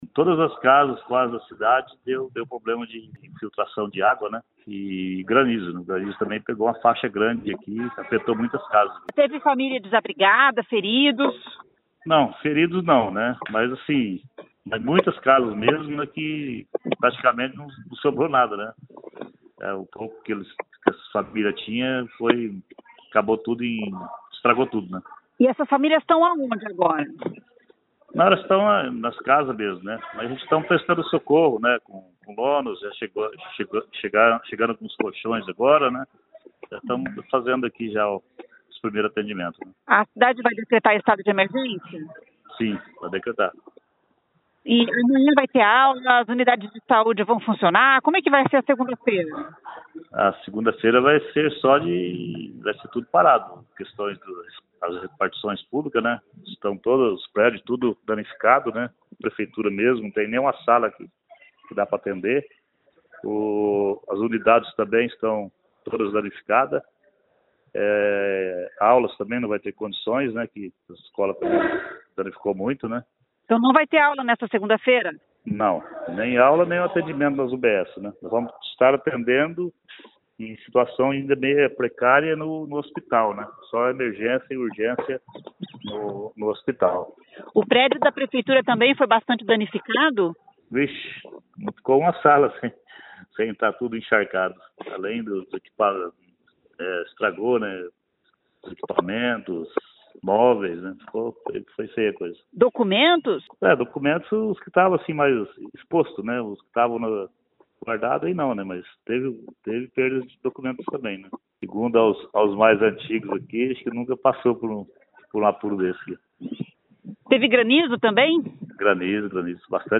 A cidade irá decretar situação de emergência. Ouça o que diz o prefeito: